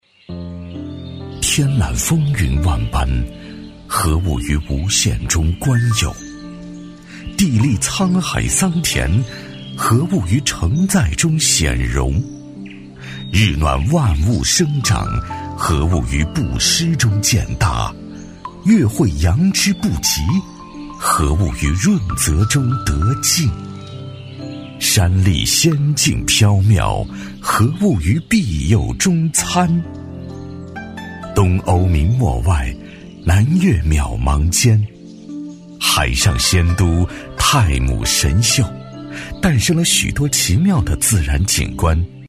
男42-走心介绍《正山堂名山》- 古韵水墨感
男42-磁性质感 高端大气
男42-走心介绍《正山堂名山》- 古韵水墨感.mp3